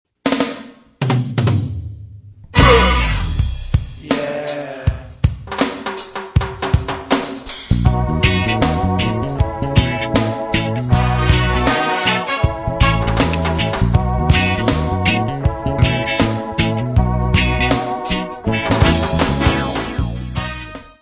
: stereo; 12 cm
Music Category/Genre:  Pop / Rock